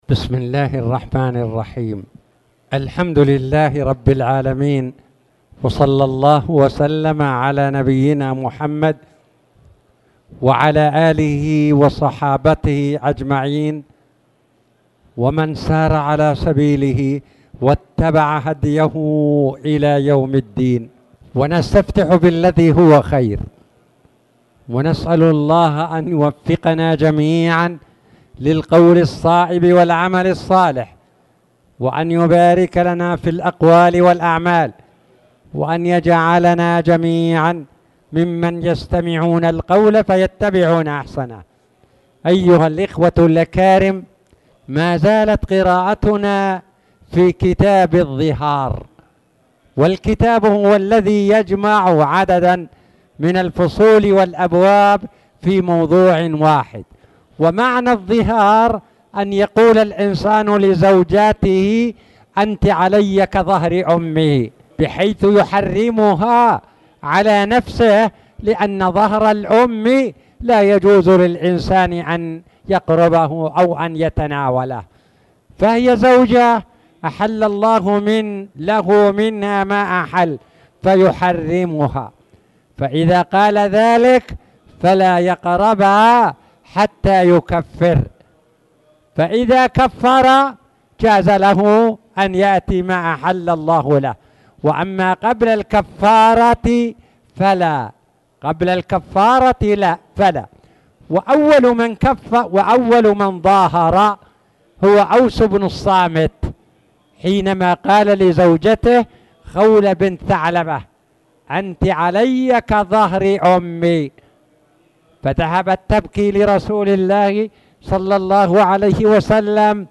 تاريخ النشر ٢٠ شعبان ١٤٣٨ هـ المكان: المسجد الحرام الشيخ